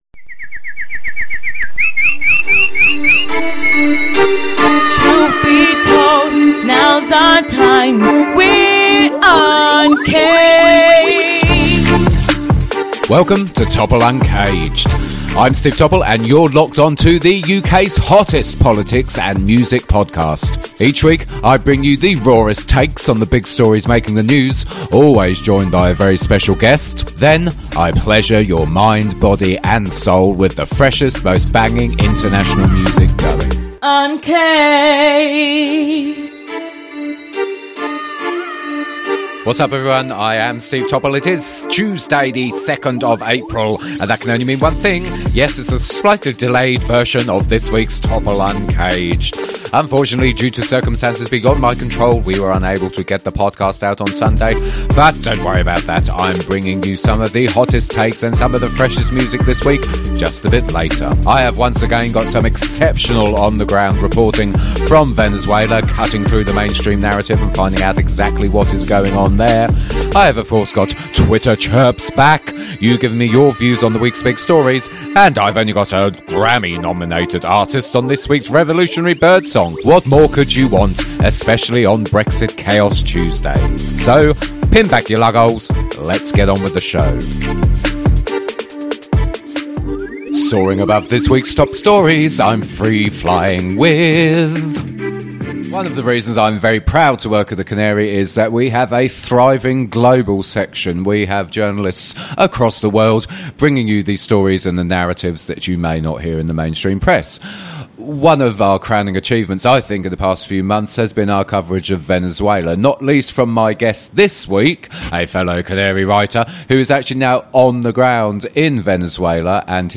THIS PODCAST CONTAINS LANGUAGE SOME PEOPLE MAY FIND OFFENSIVE